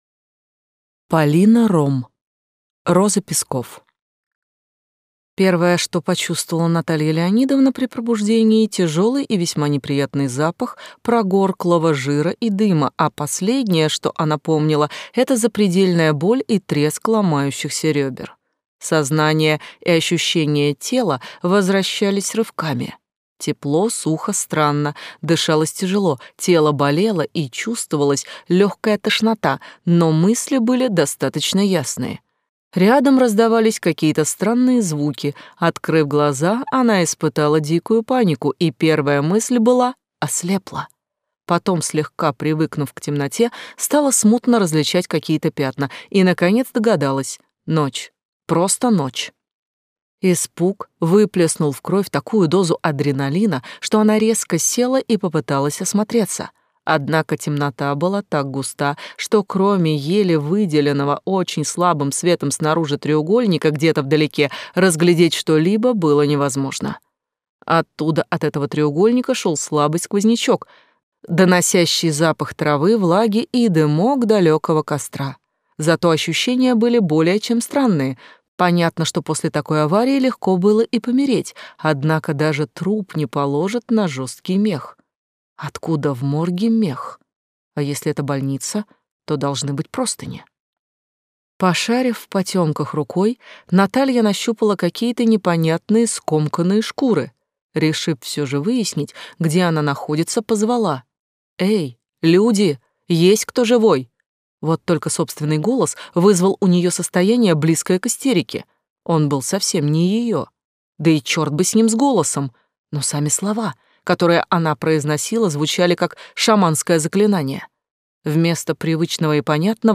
Аудиокнига Роза песков | Библиотека аудиокниг